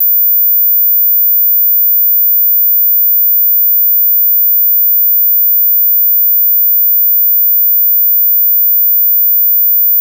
Bild 3: samma fil som i bild 1 har körts genom Addistortion men denna gång med adderad symmetrisk olinjäritet dvs. sådan som skulle ge en ren tredje ton (-30dB) på en ensam liten sinus.